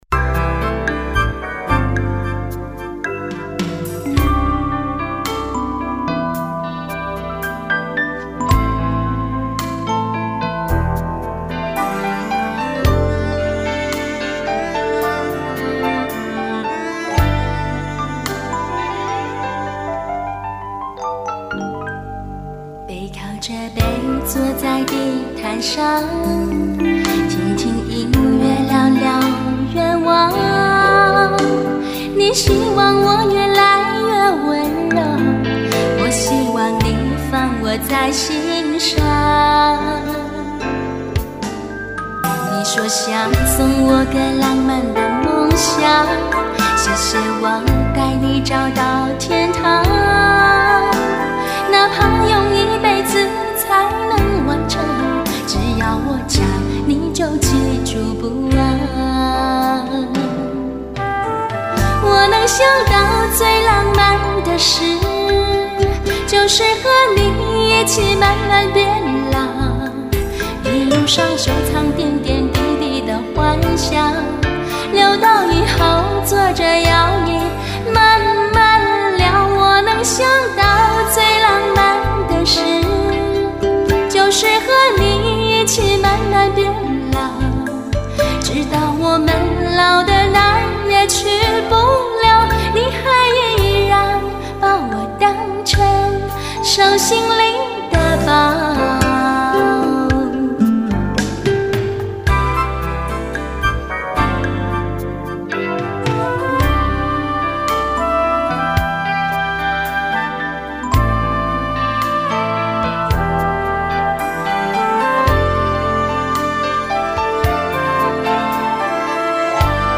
声音太甜， 骨头都馊了。
• 好听得不要不要滴呀，甜蜜娇羞，听出耳油。那时候的音色水当当脆生生的，有种不谙世事的纯真，和对未来美好的憧憬。